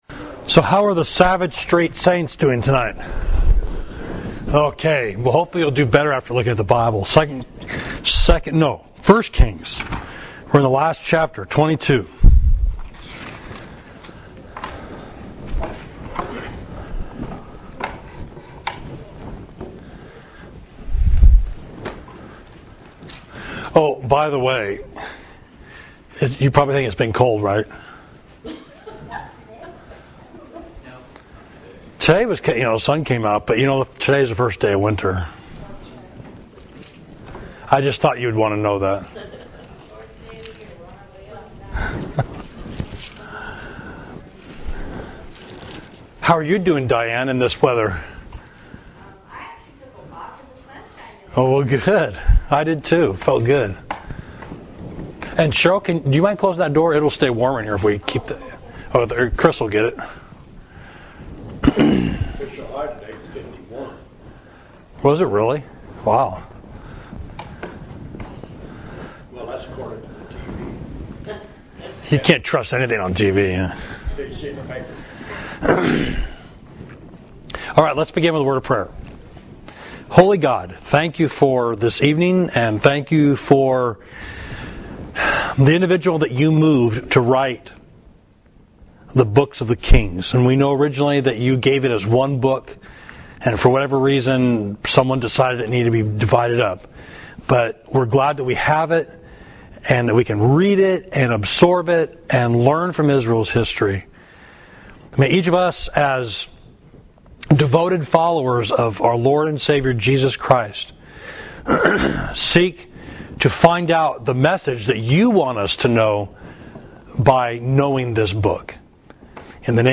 Class: Ahab’s End, 1 Kings 22 – Savage Street Church of Christ